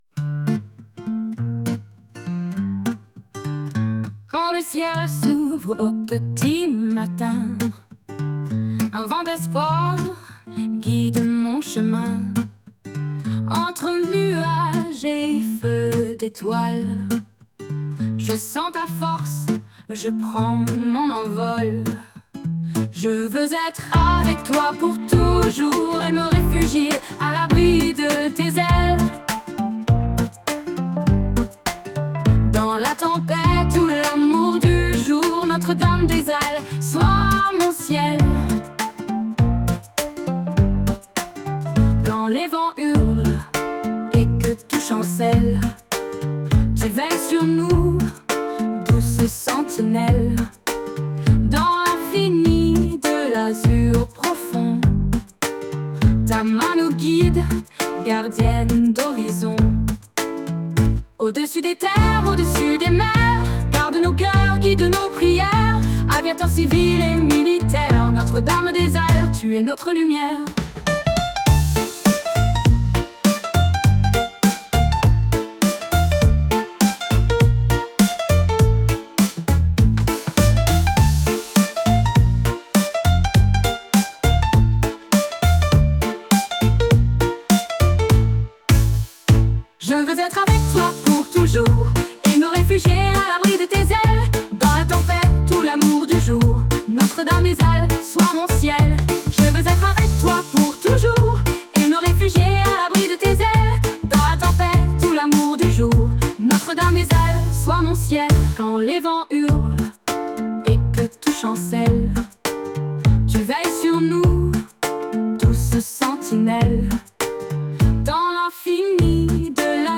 Chanson Notre Dame des Ailes